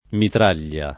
mitraglia [ mitr # l’l’a ]